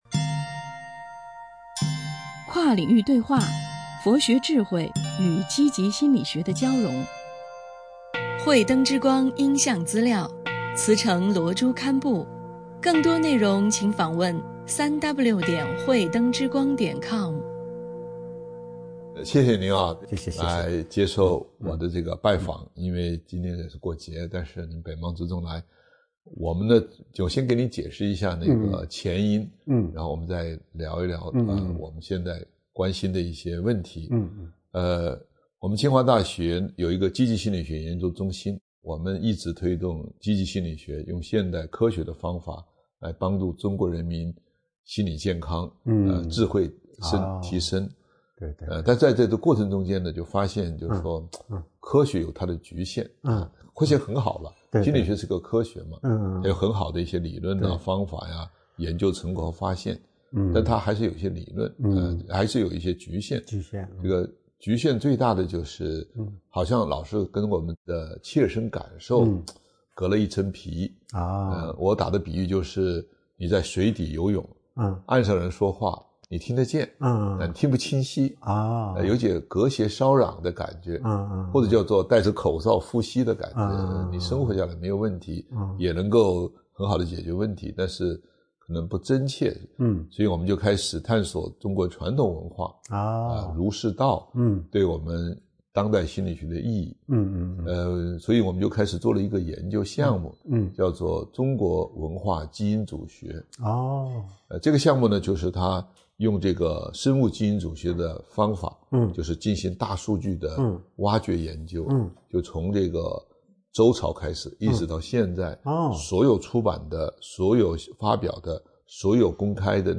跨领域对话：佛学智慧与积极心理学的交融.mp3